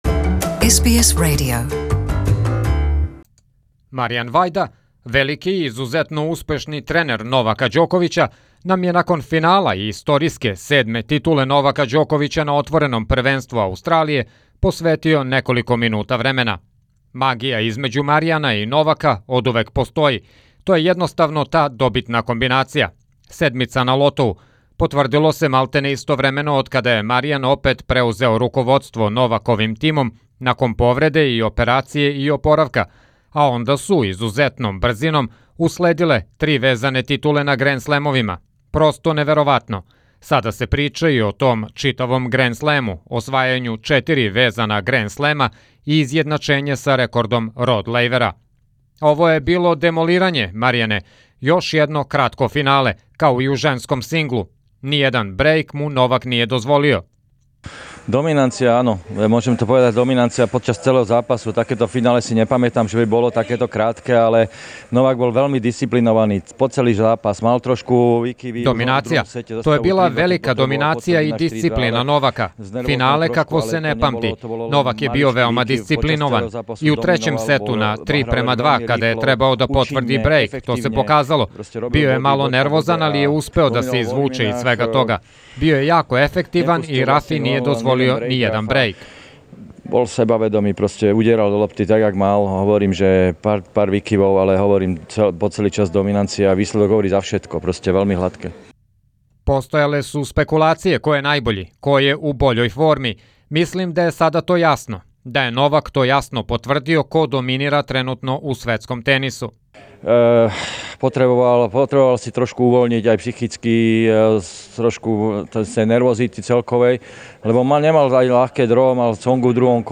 (Слушајте адаптиран интервју )